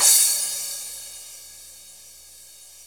Crash 02.wav